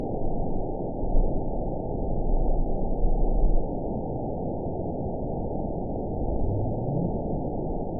event 922741 date 03/24/25 time 22:43:07 GMT (1 month, 1 week ago) score 9.39 location TSS-AB10 detected by nrw target species NRW annotations +NRW Spectrogram: Frequency (kHz) vs. Time (s) audio not available .wav